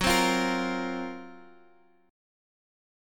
F#M7b5 chord